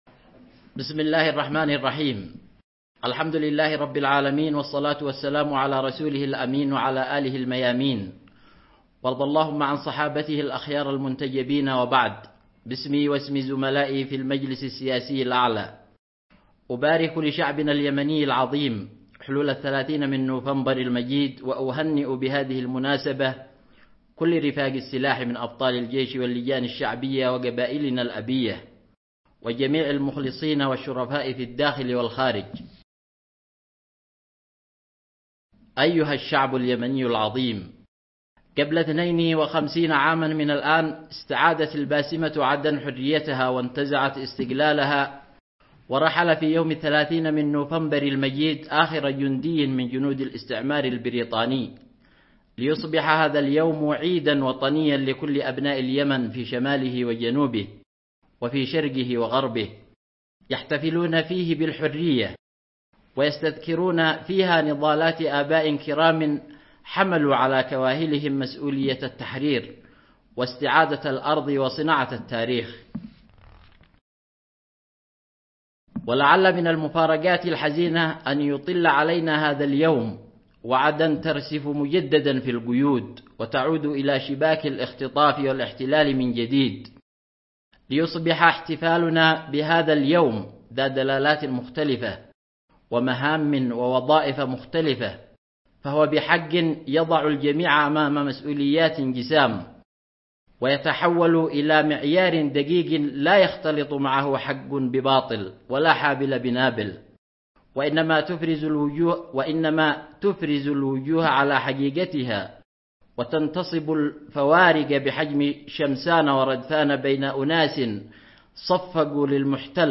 كلمة رئيس المجلس السياسي الأعلى مهدي المشاط بمناسبة العيد الـ52 للاستقلال 30 من نوفمبر
خطاب فخامة الأخ مهدي المشاط رئيس الجمهورية
بمناسبة الذكرى الثانية والخمسون لعيد الاستقلال الوطني